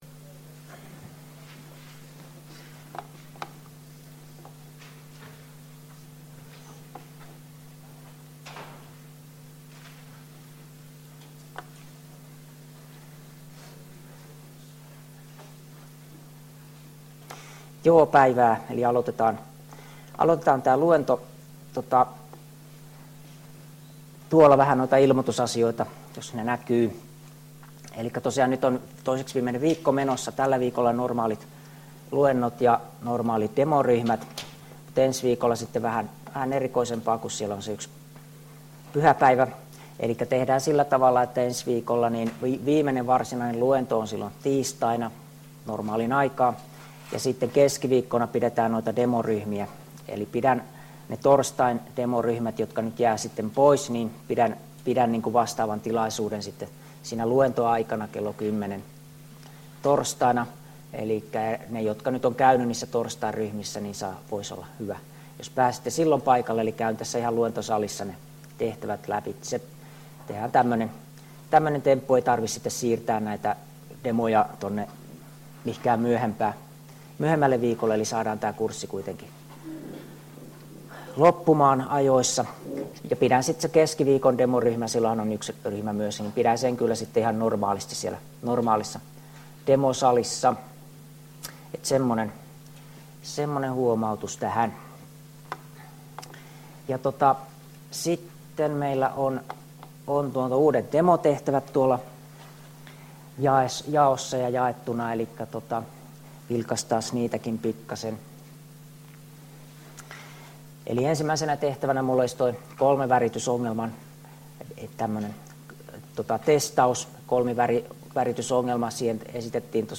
Luento 11 — Moniviestin